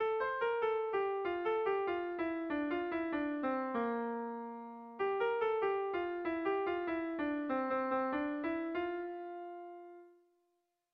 Air de bertsos - Voir fiche   Pour savoir plus sur cette section
8 / 8A / 8 / 8A (hg) | 16A / 16A (ip)
AB